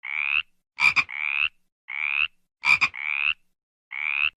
Katak_Suara.ogg